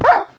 sounds / mob / wolf / hurt2.ogg
hurt2.ogg